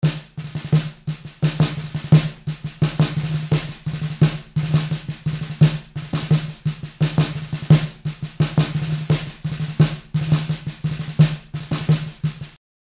tambor.mp3